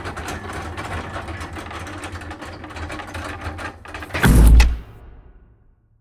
Gate1.ogg